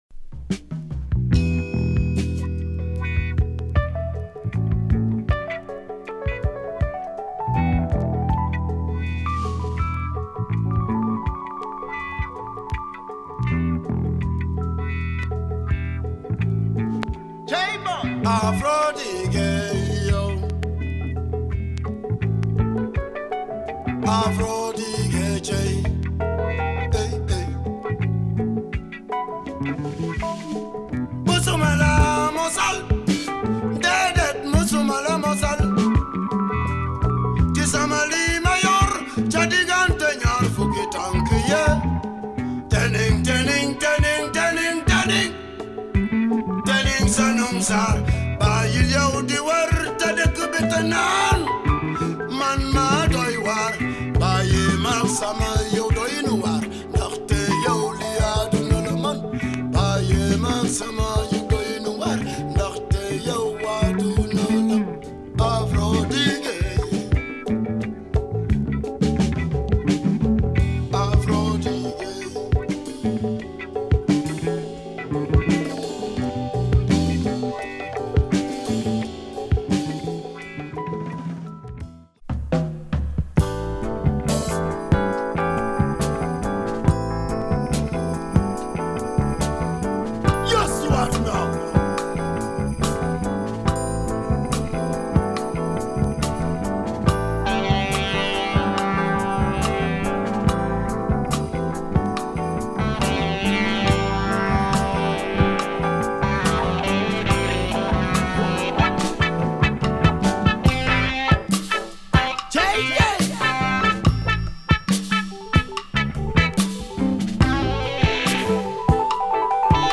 Superb copy of this afro funk masterpiece.